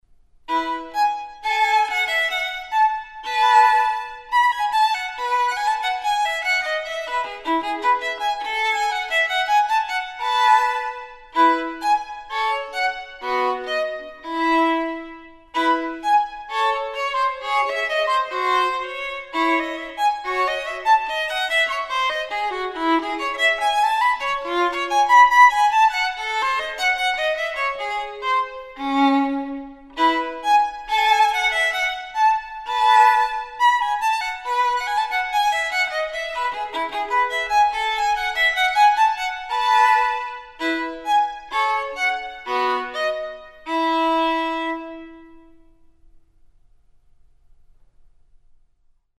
musicViolinAmati1570.mp3